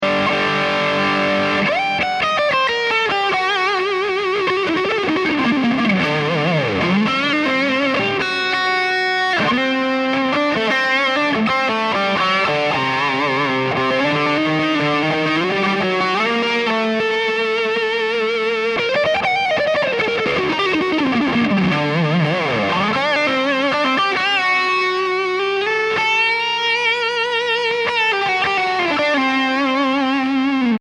Yeah--the "gain" knob should be called the "noise" knob, but if you keep the gain down and use the level to dial it in, is does some pretty bad-ass things. Put it in front of my TriAmp MK II on channel 2B and I get mega-crunch rhythms... in front of 3A or 3B, fluid metal leads with sustain for...